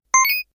debian_pickup.wav